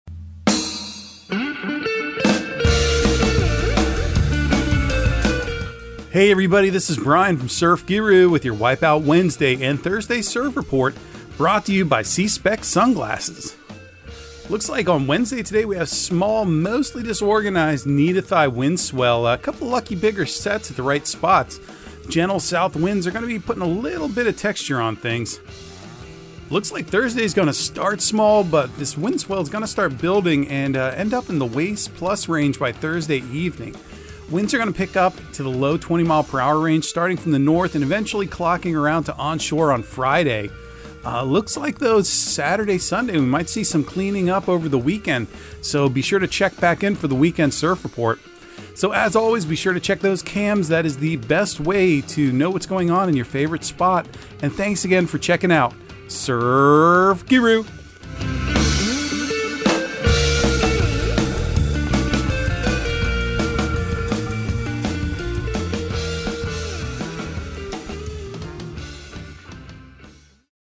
Surf Guru Surf Report and Forecast 11/30/2022 Audio surf report and surf forecast on November 30 for Central Florida and the Southeast.